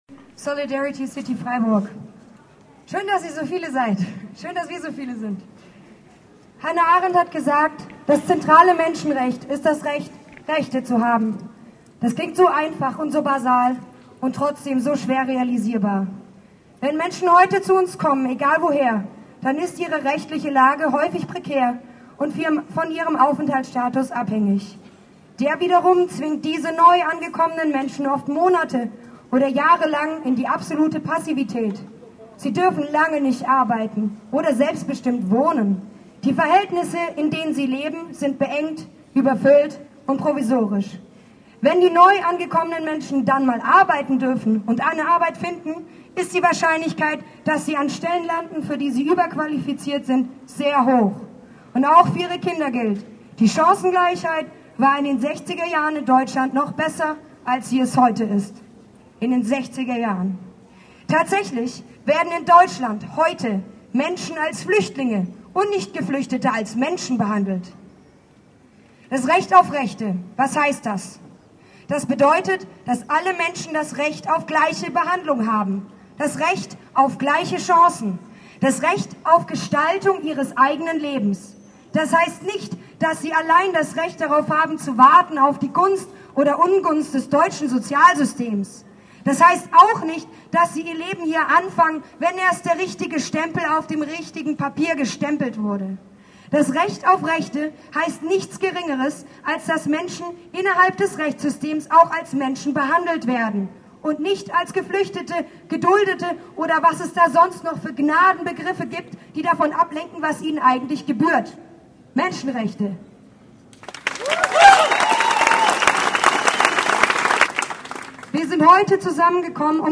RDL war beim spätabendlichen Protest und sammelte einige Eindrücke.